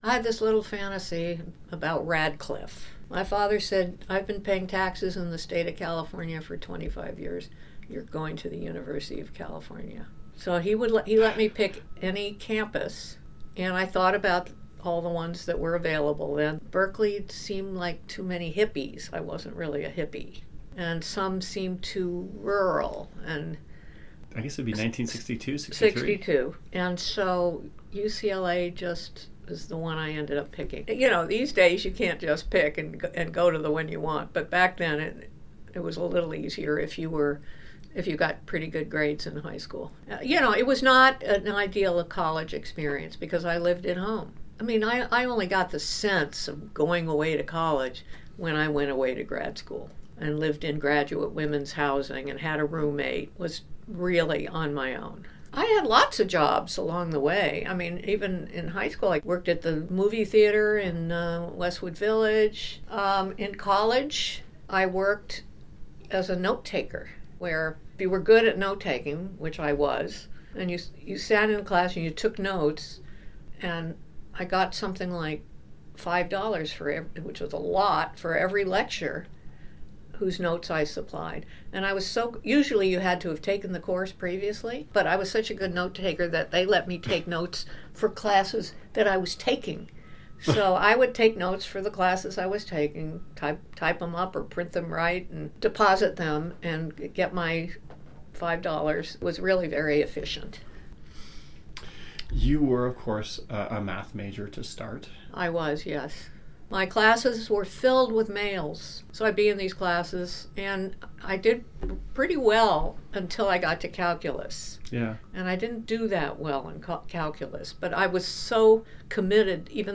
In this next excerpt, Dr. Loftus describes her journey into Psychology and grad school: